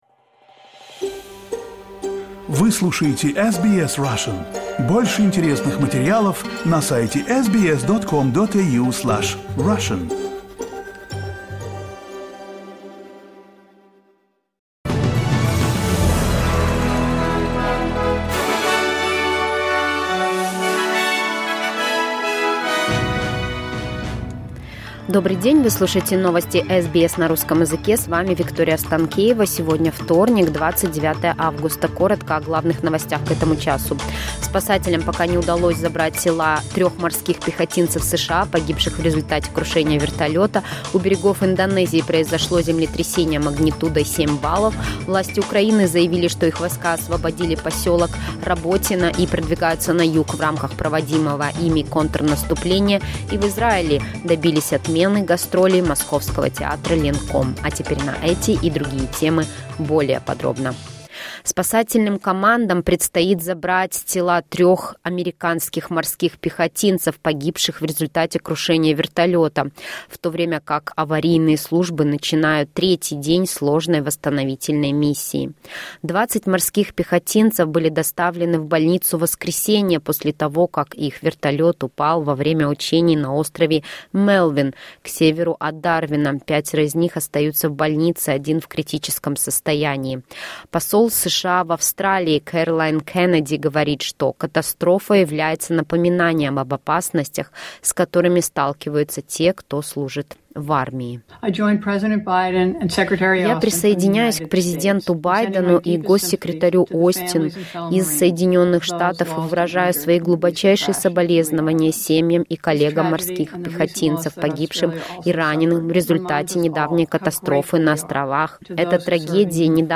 SBS news in Russian — 29.08.2023